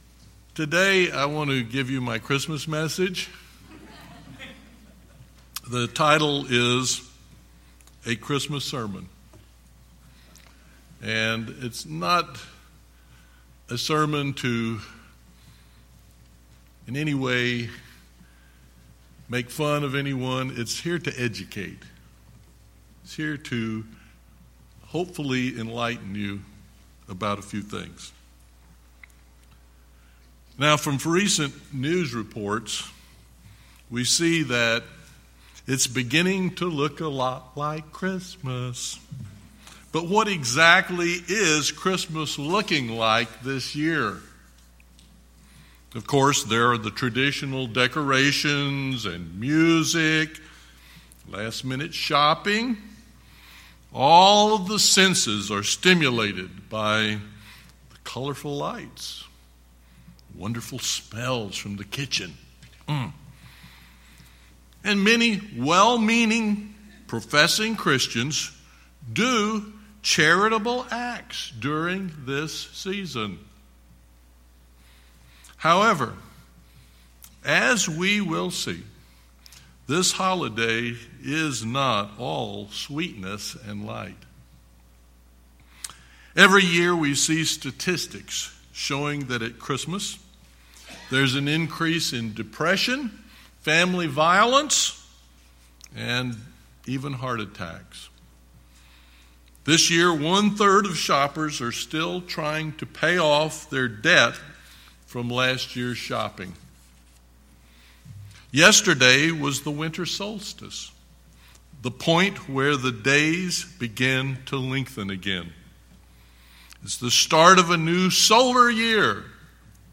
A Christmas Sermon